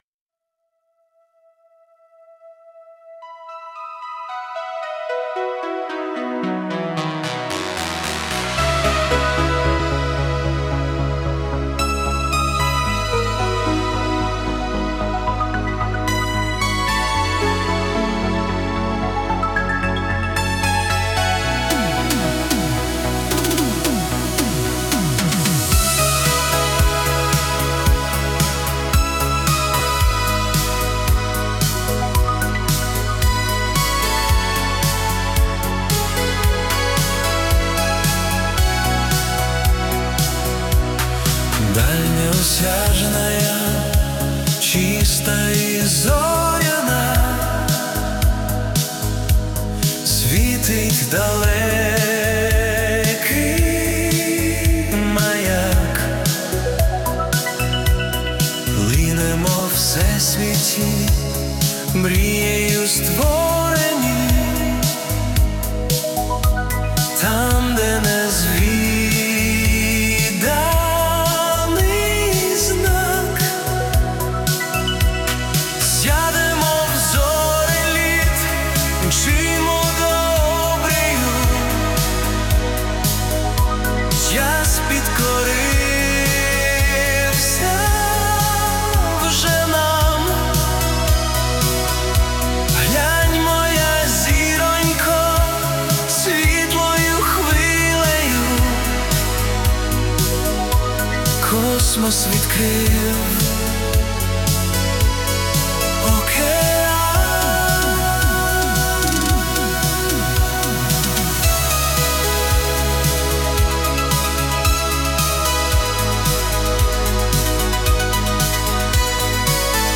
🎵 Жанр: Space Synth / Dream Pop